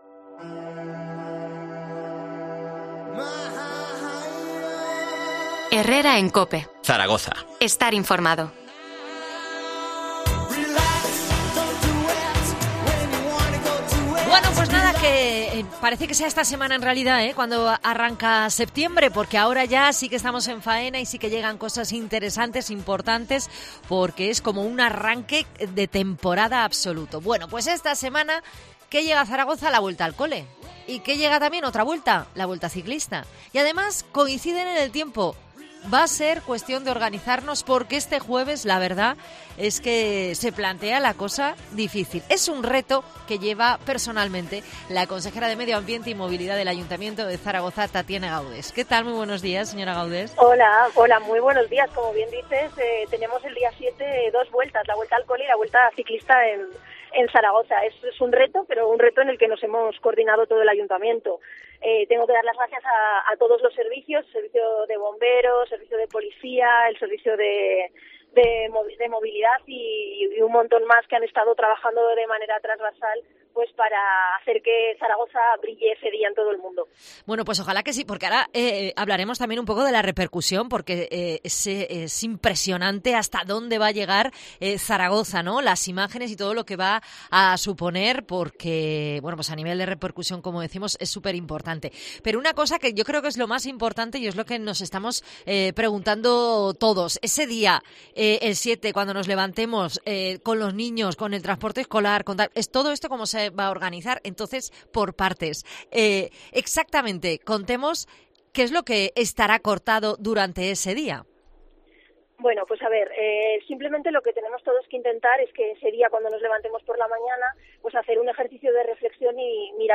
Entrevista a la consejera de Movilidad, Tatiana Gaudes, sobre la llegada de la Vuelta a Zaragoza.